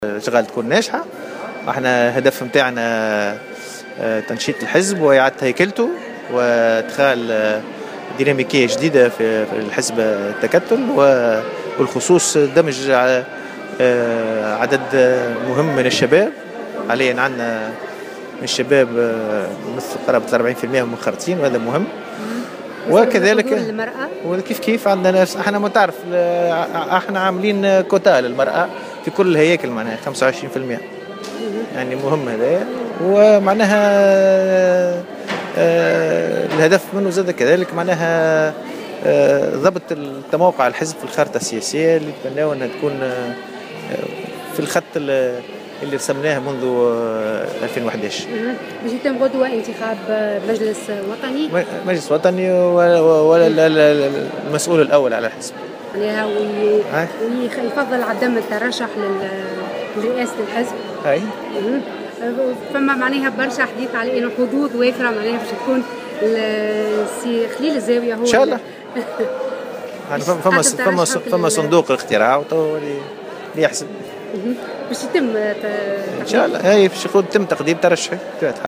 وذلك على هامش أشغال مؤتمر التكتل الثالث المنعقد حاليا في الحمامات.